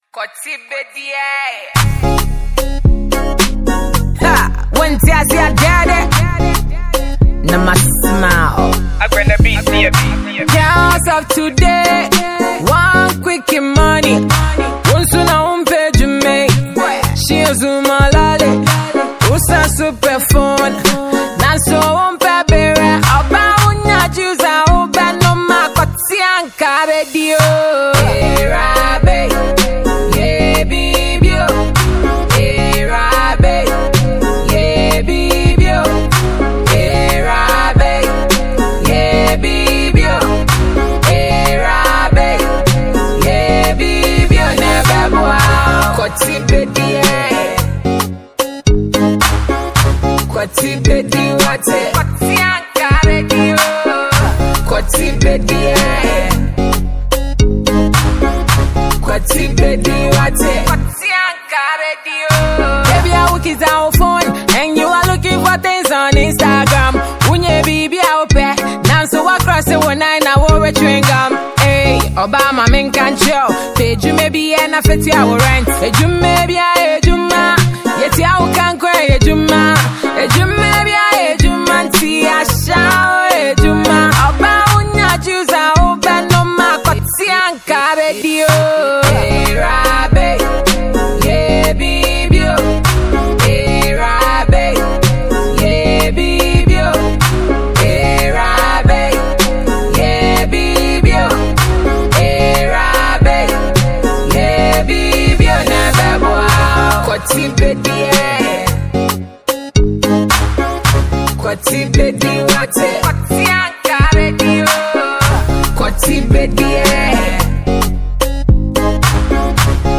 Ghanaian Female Rap diva